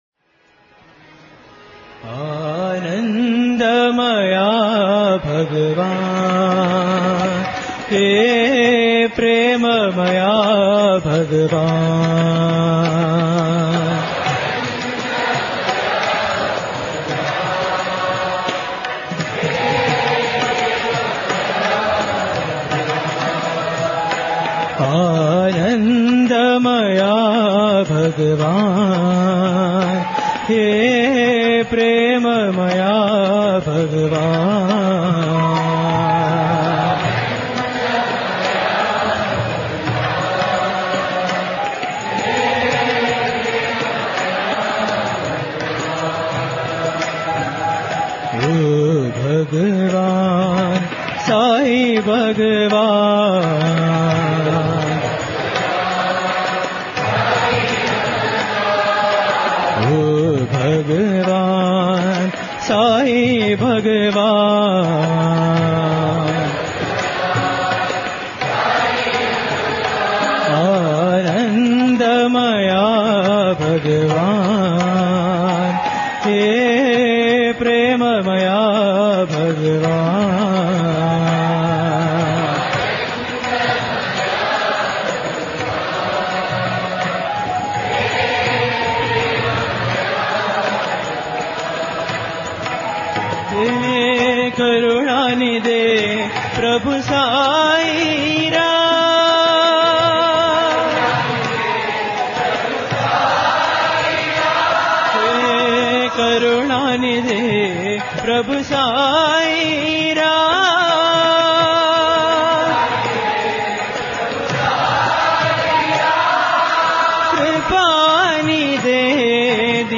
Bhajan